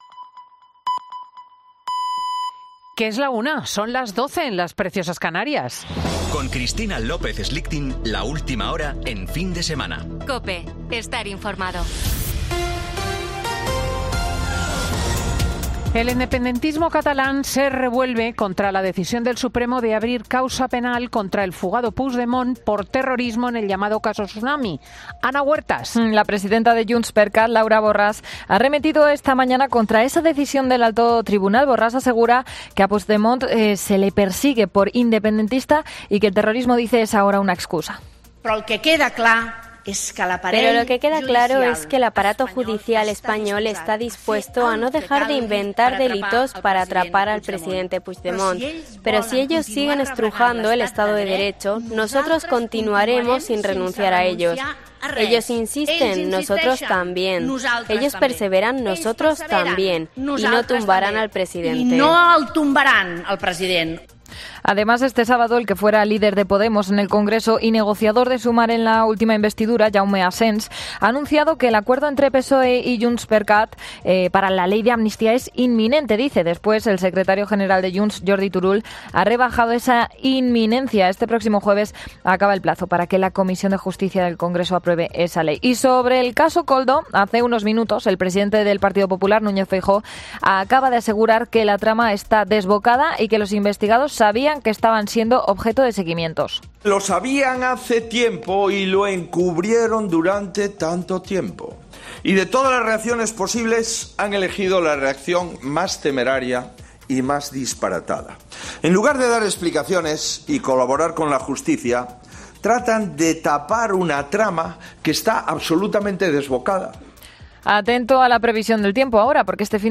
Boletín 13.00 horas del 2 de marzo de 2024